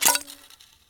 bow_fall.L.wav